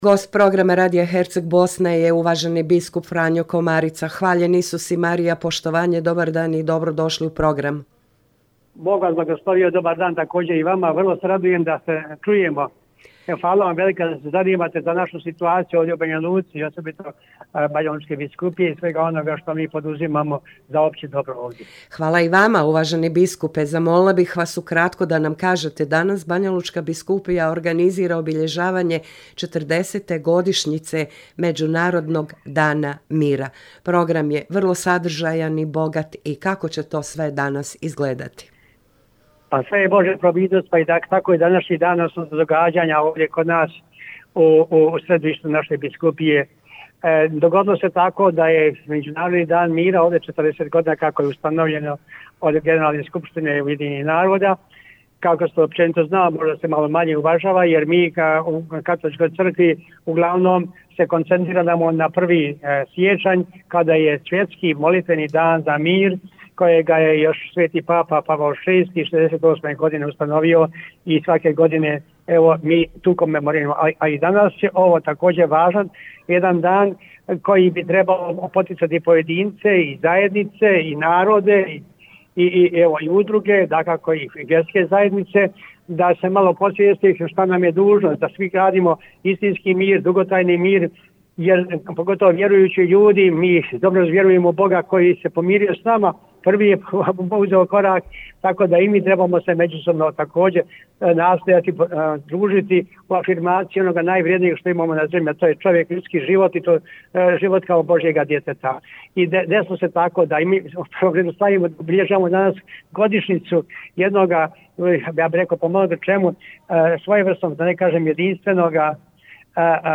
AUDIO: GOST U PROGRAMU RADIJA HERCEG BOSNE JE BIO BISKUP FRANJO KOMARICA